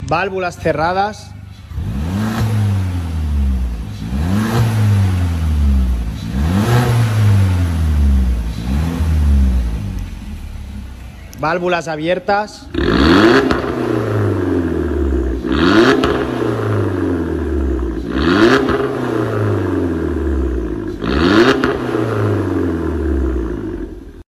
VW Scirocco 1.4 TSI con sound effects free download
VW Scirocco 1.4 TSI con intermedio y final con válvulas